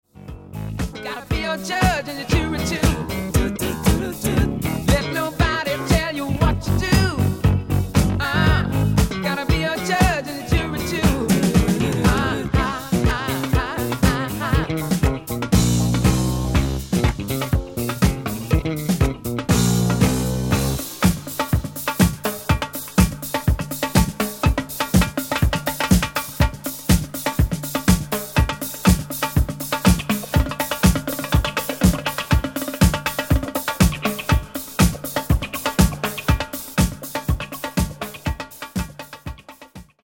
Genere:   Disco | Funky